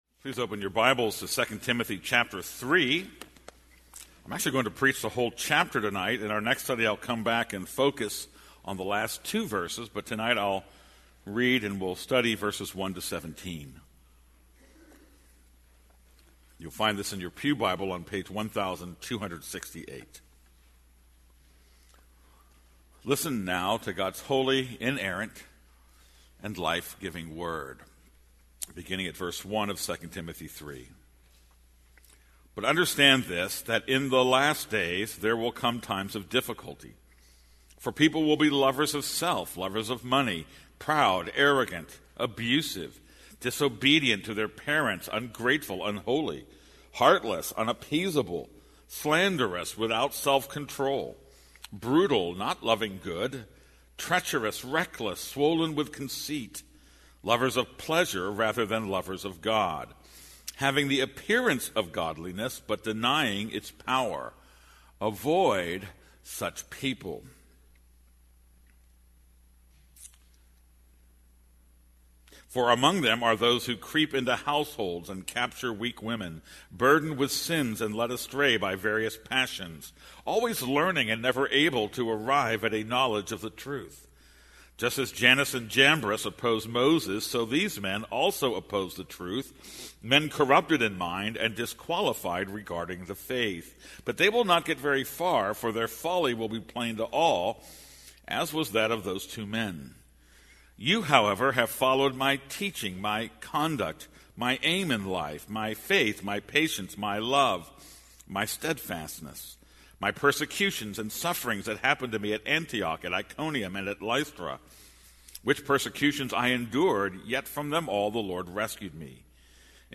This is a sermon on 2 Timothy 3:1-17.